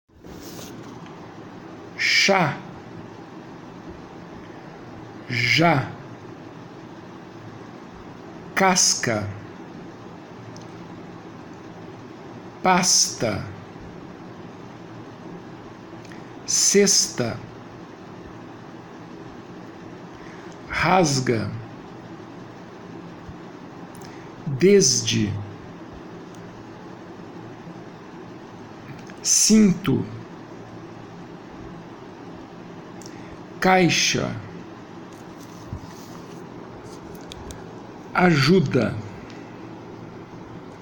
Faça download dos arquivos de áudio e ouça a pronúncia das palavras a seguir para transcrevê-las foneticamente.
GRUPO 8 - Sibilantes - Arquivo de áudio -->